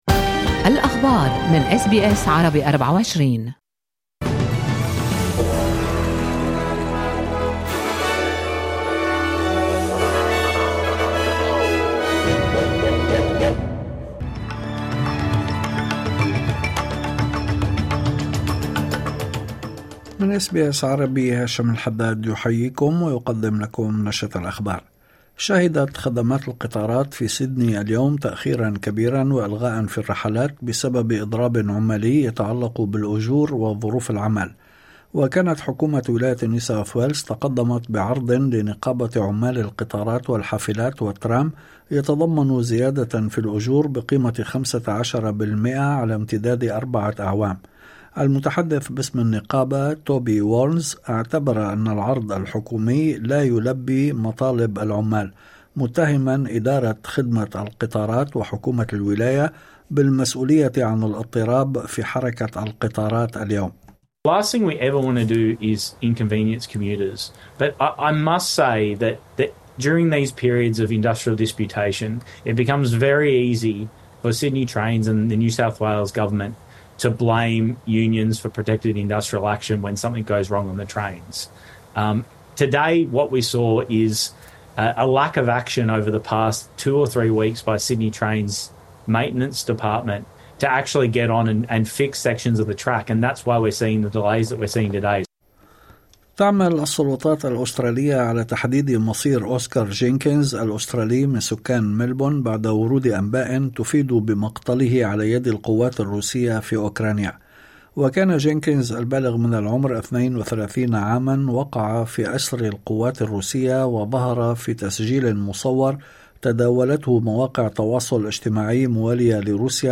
نشرة أخبار الظهيرة 15/01/2025
نشرة الأخبار